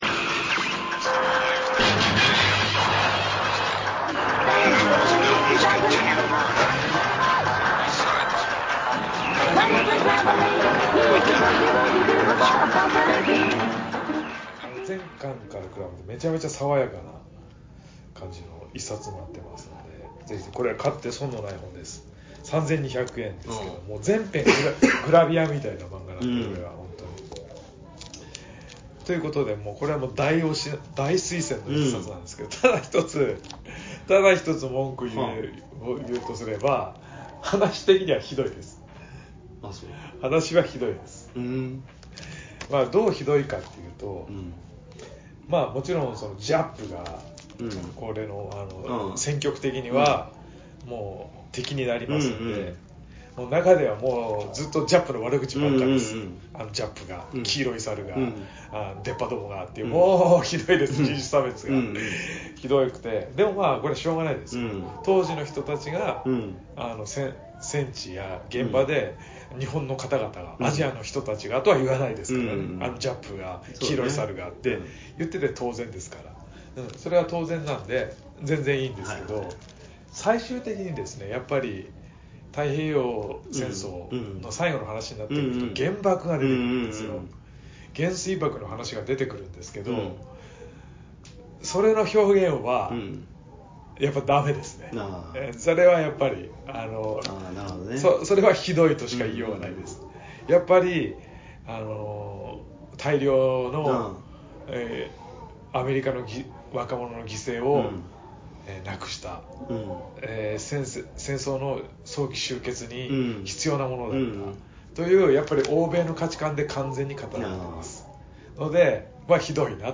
アメコミやバンドデシネがちょっと好きで、ちょっとアレな二人の男子が、至高の女子会を目指すエンタテインメントネットラジオです。